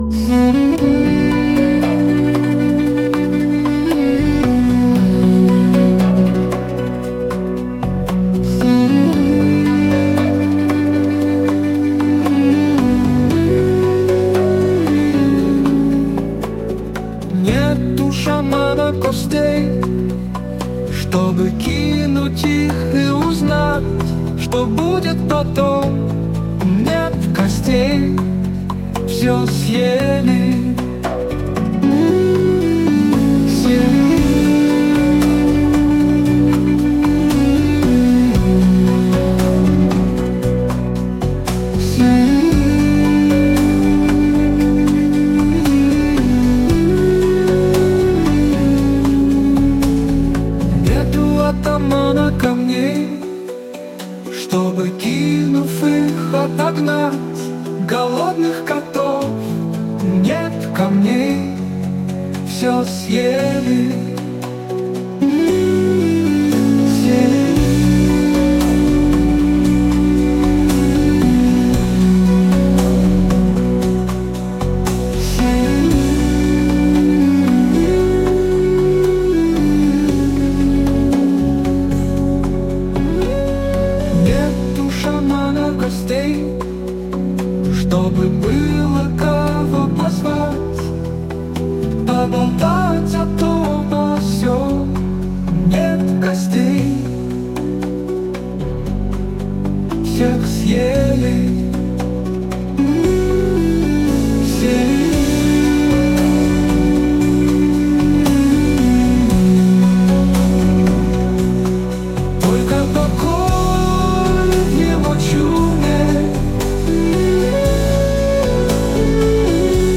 • Жанр: AI Generated